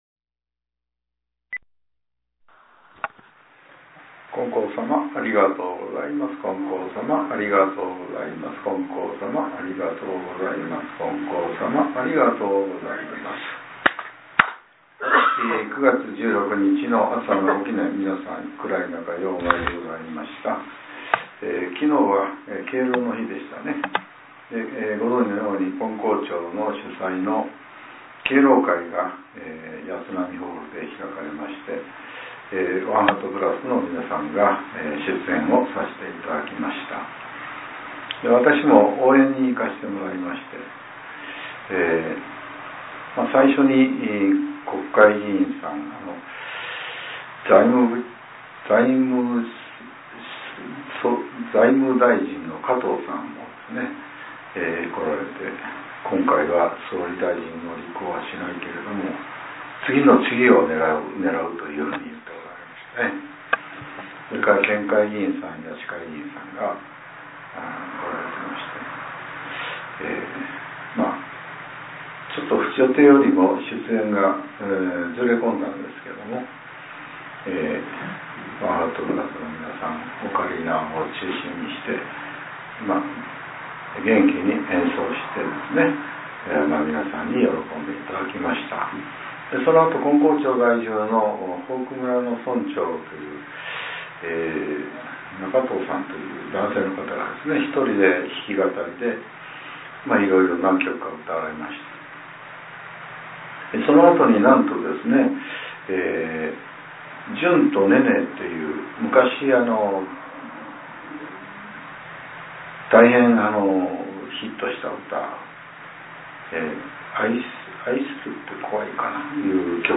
令和７年９月１６日（朝）のお話が、音声ブログとして更新させれています。